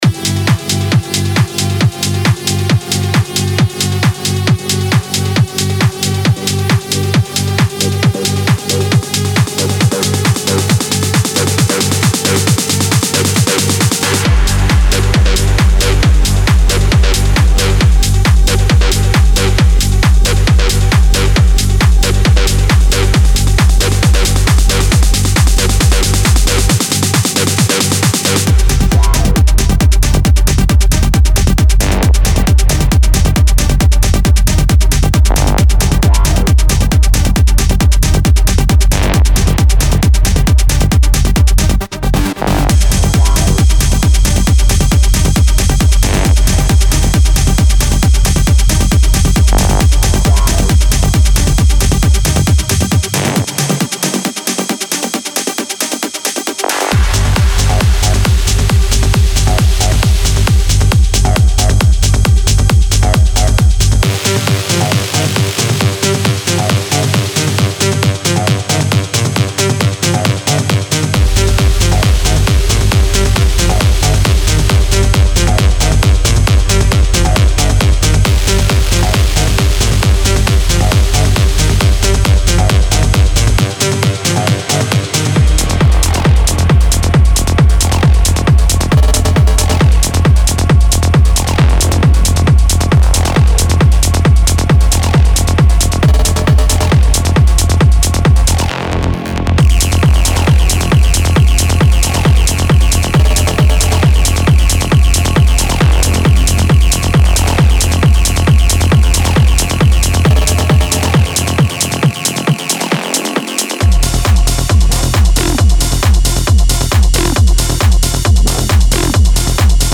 Genre:Techno
このパックは、ピークタイムでの破壊力を念頭に作られた、ハイボルテージで催眠的なテクノを提供します。
テクスチャーはクリアで、エネルギーは圧倒的、雰囲気は純粋なウェアハウスの激しさです。
デモサウンドはコチラ↓
15 Acid Synth Loops